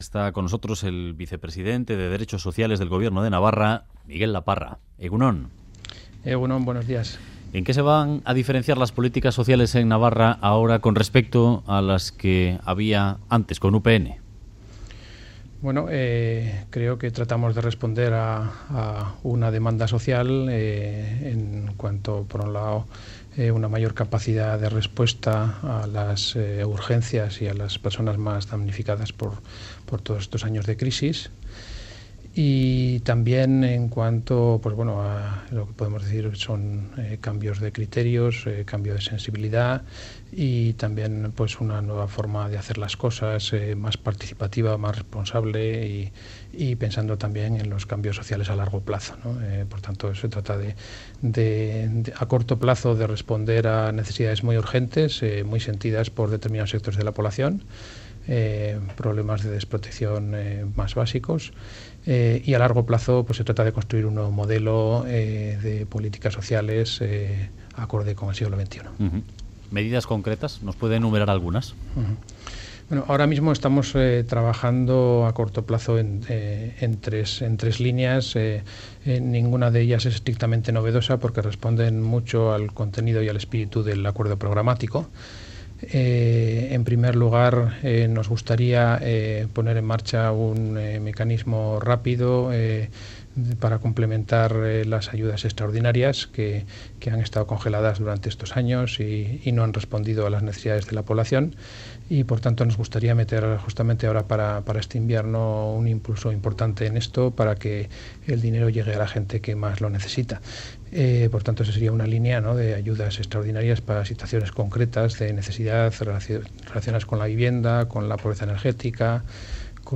Radio Euskadi BOULEVARD Miguel Laparra defiende un nuevo modelo de políticas sociales Última actualización: 01/09/2015 10:40 (UTC+2) El vicepresidente de derechos sociales del gobierno de Navarra, Miguel Laparra ha defendido hoy en Boulevard de Radio Euskadi, la necesidad de construir a largo plazo un nuevo modelo de políticas sociales.